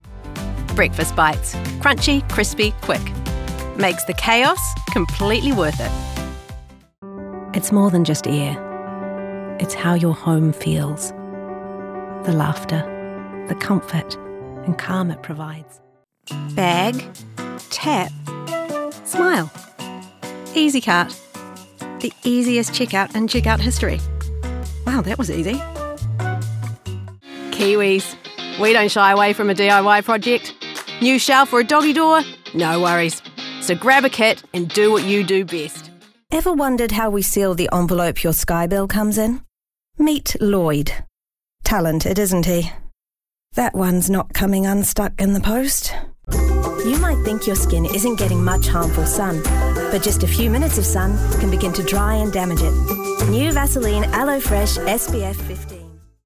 COMMERCIAL 💸
warm/friendly